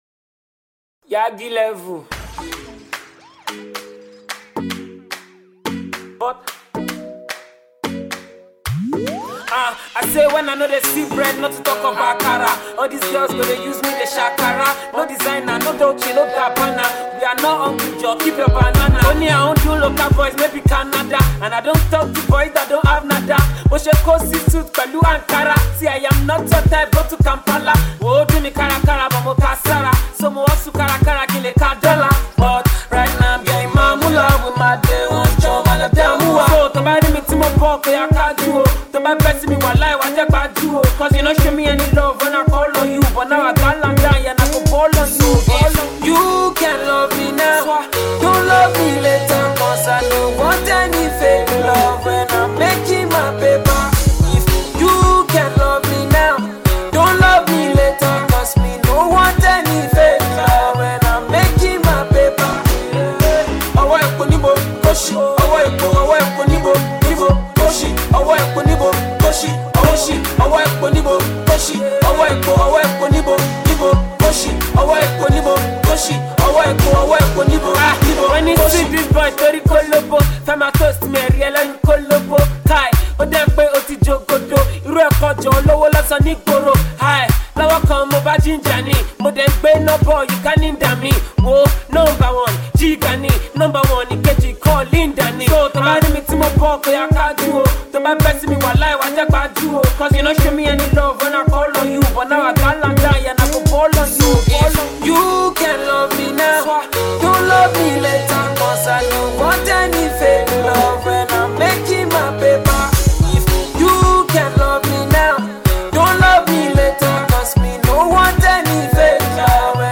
The song comes across as melodious